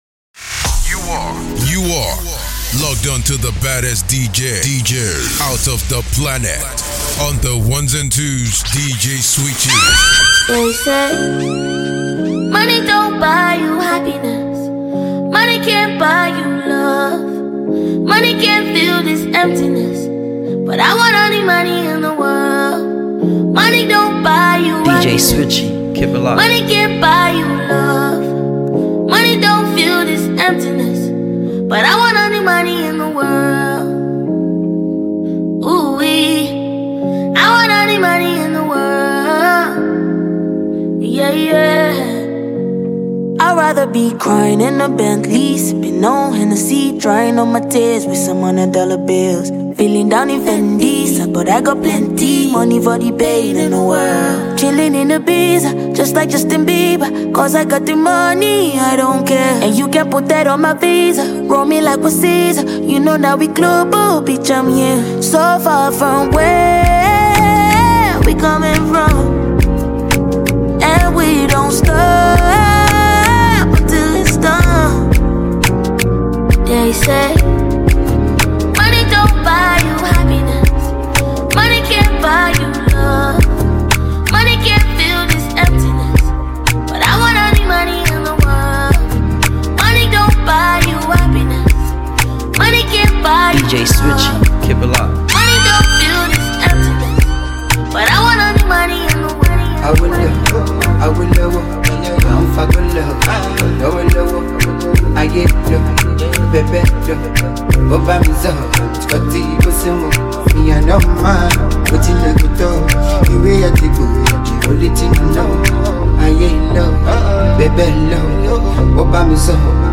Talented Nigerian disc jockey
this new Dj mix consist of trending hit songs in 2025.